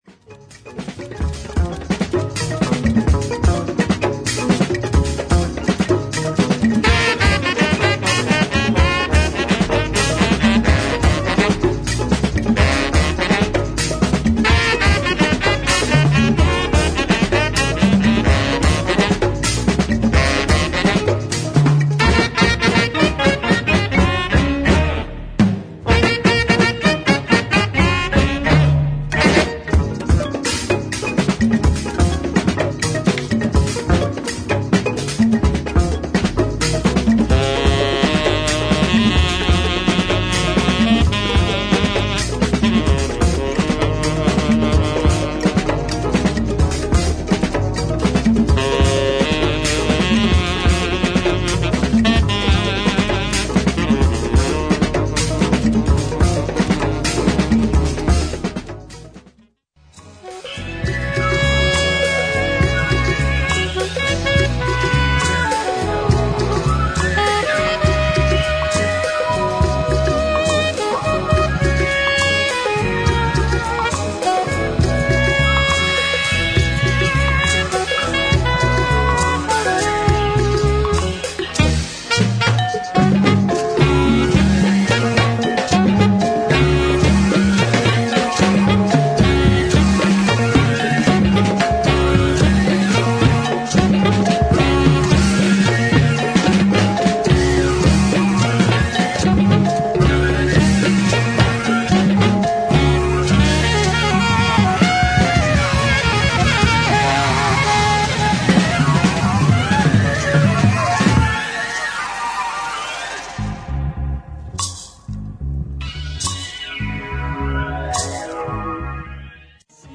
軽やかなスティールパンのリフがカリビアンな雰囲気を醸し出す
メロウでスピリチュアルな